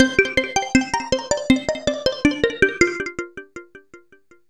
Ambient / Keys / SYNTH032_AMBNT_160_C_SC3.wav
1 channel